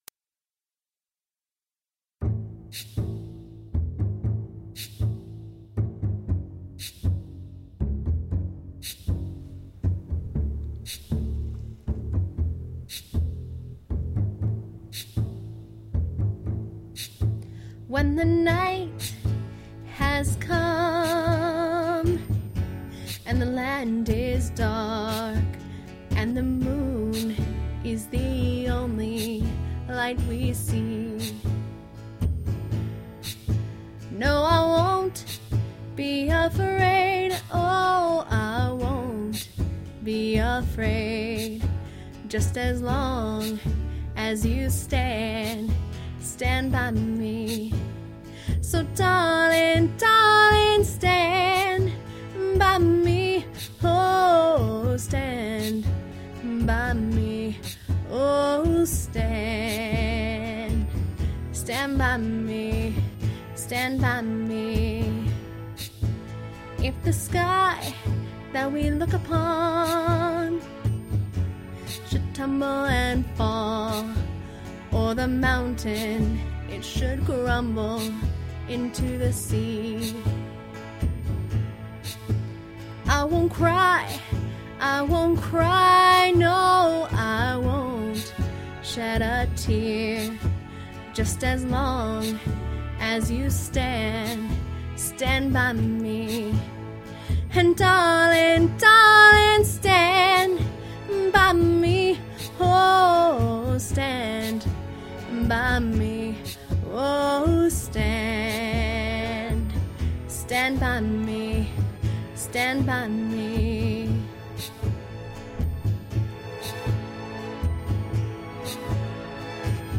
😀 I sang on top of an mp3 go me!
cover
singing